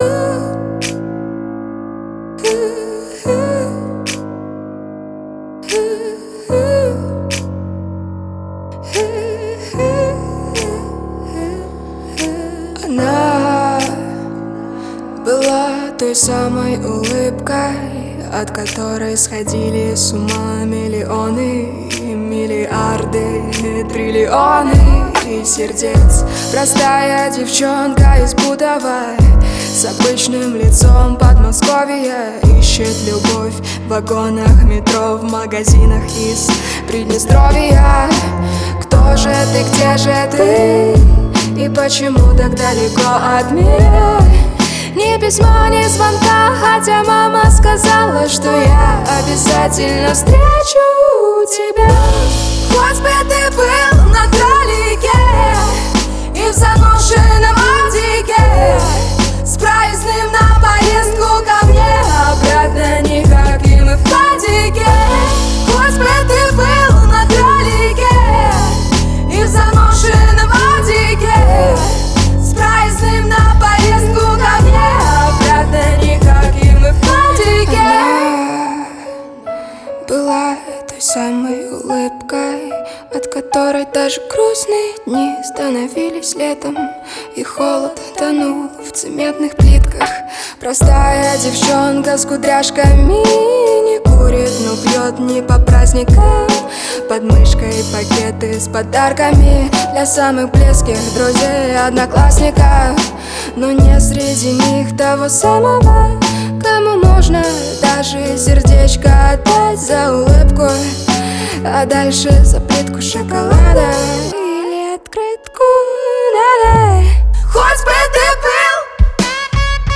это трек в жанре поп с элементами рэпа